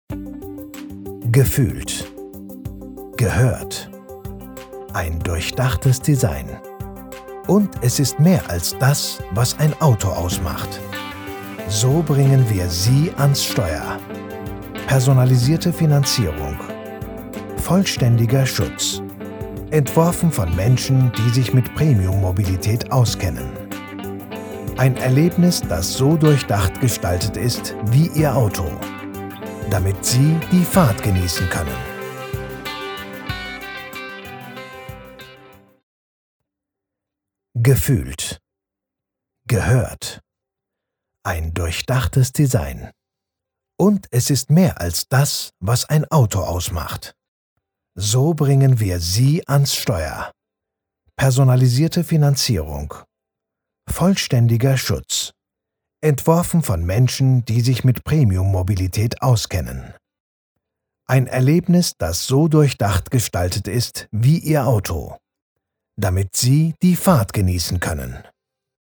Synchronsprecher in 4 Sprachen: Deutsch, Englisch, Türkisch und Arabisch.
Kein Dialekt
Sprechprobe: Werbung (Muttersprache):